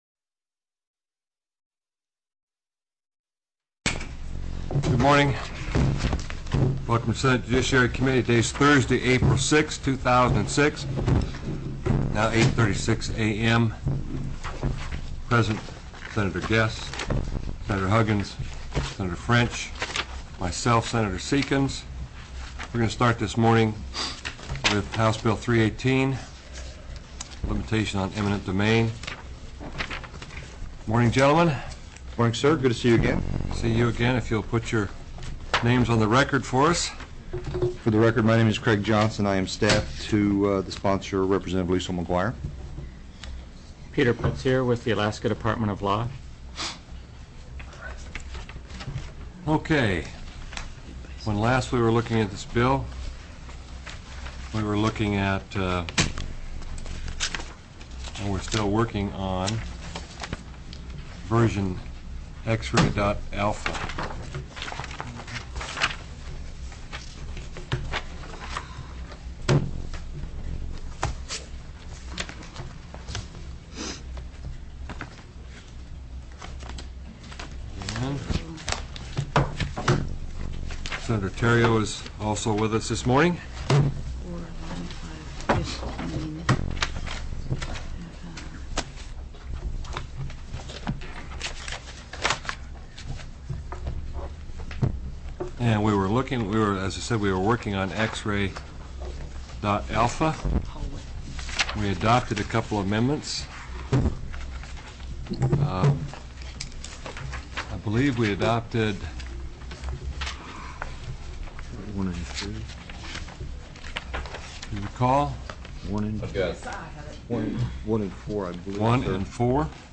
04/06/2006 08:30 AM Senate JUDICIARY
HB 272 CARD ROOMS & OPERATIONS TELECONFERENCED Heard & Held